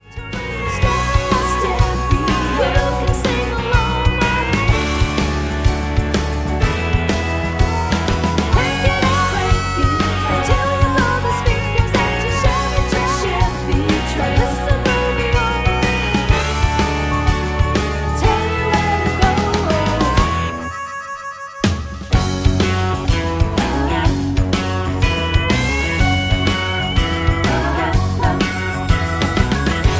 Backing track files: Country (2471)
Buy With Backing Vocals.